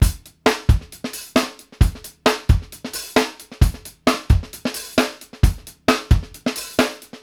BASIC FUNK-R.wav